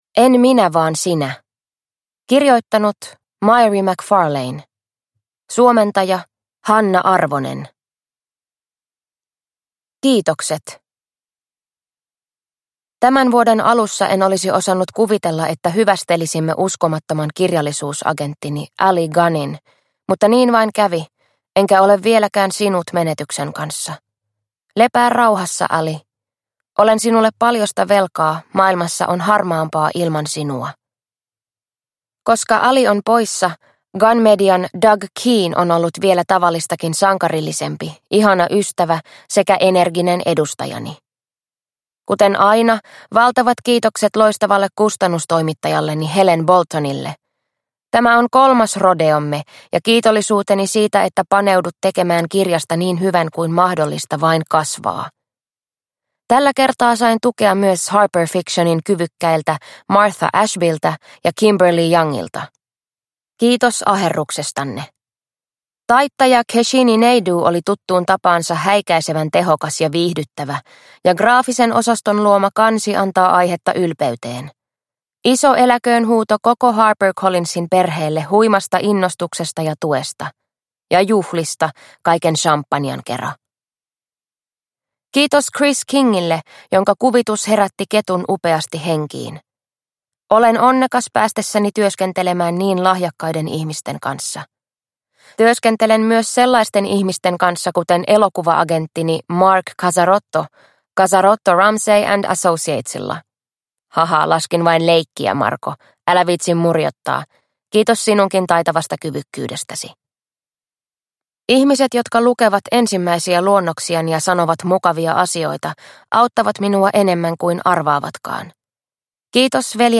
En minä vaan sinä – Ljudbok – Laddas ner